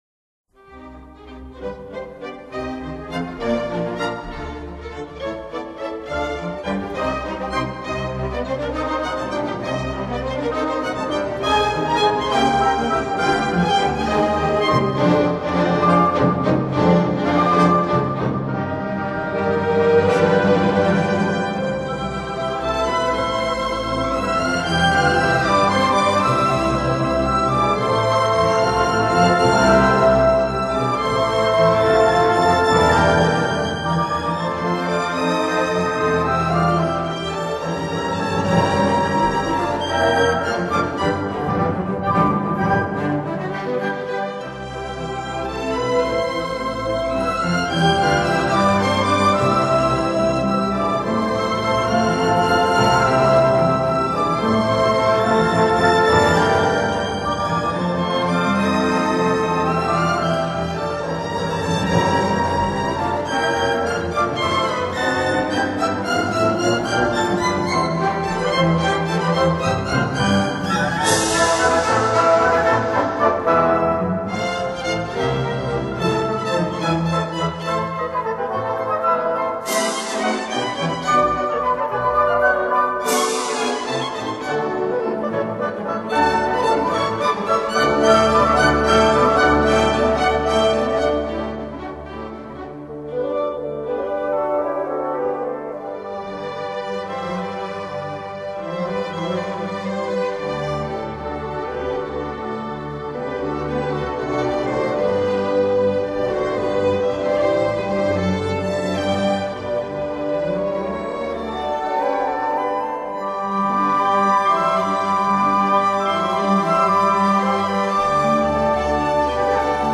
Allegro animato